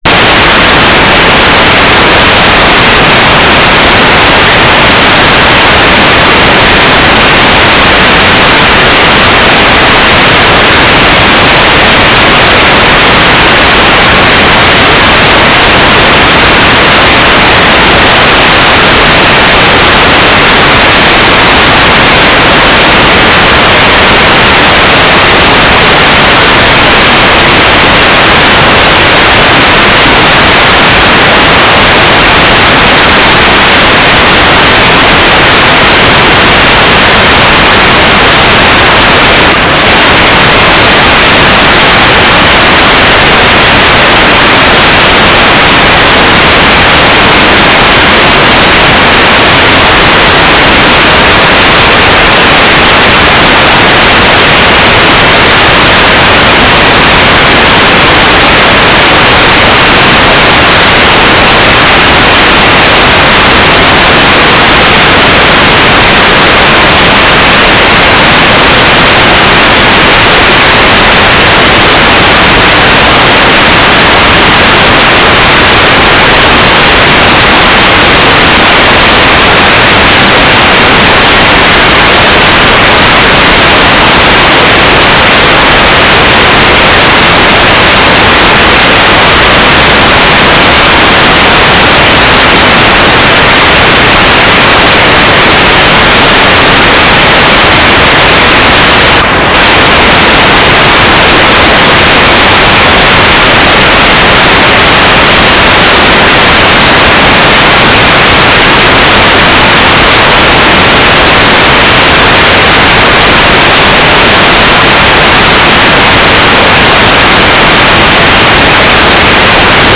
"transmitter_mode": "GMSK",